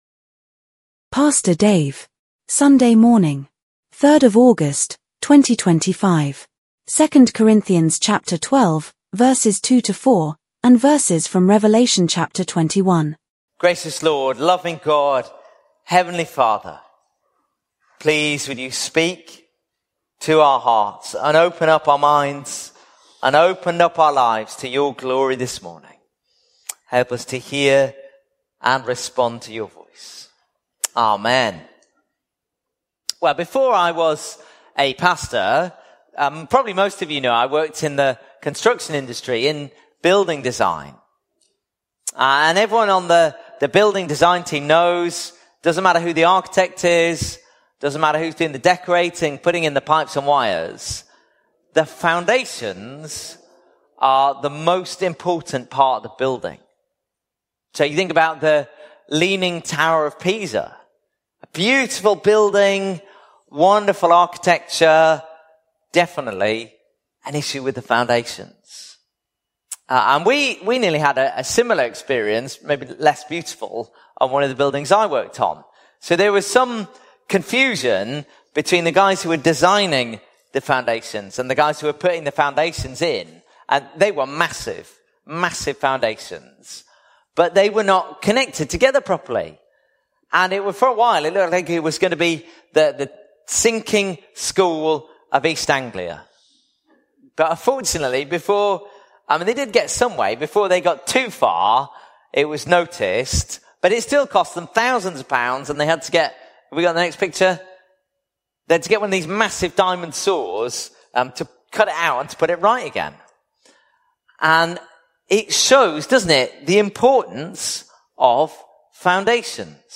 Notes Sermons in this Series Sunday 24 August 2025